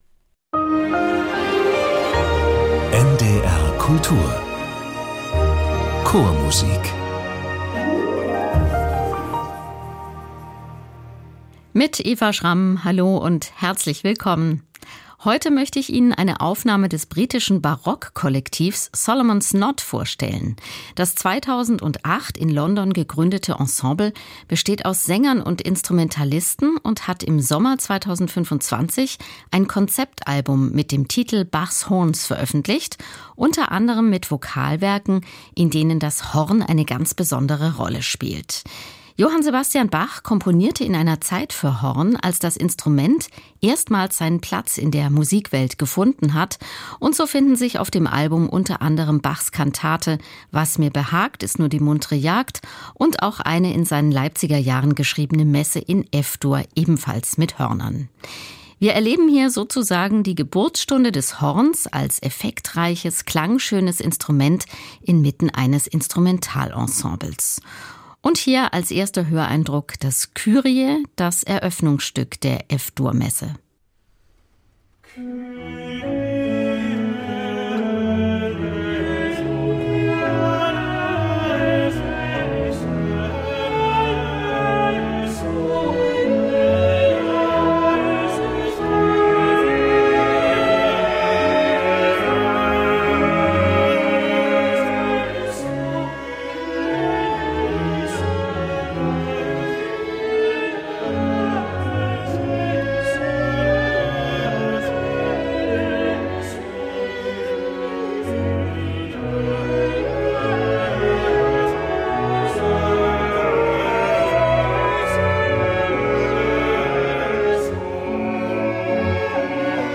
Bach's Horns - singende Hornklänge in Kantaten und Messen ~ Chormusik - Klangwelten der Vokalmusik entdecken Podcast